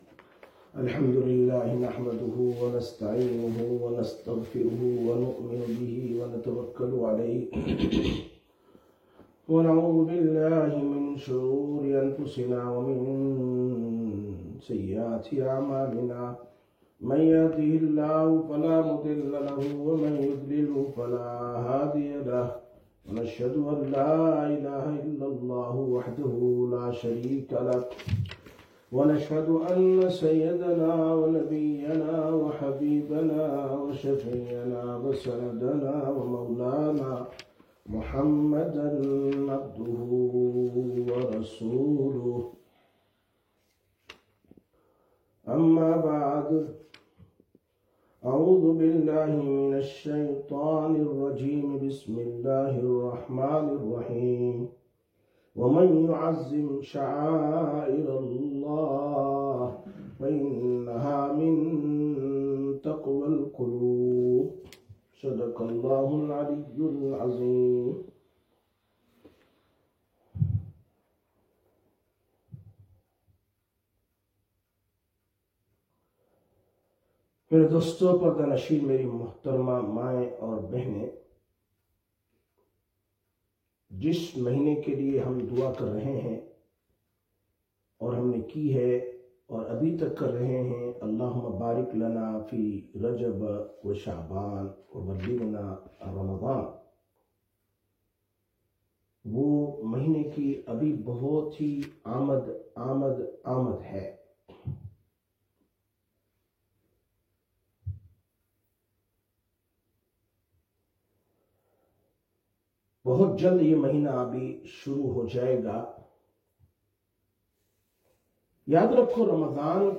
11/02/2026 Sisters Bayan, Masjid Quba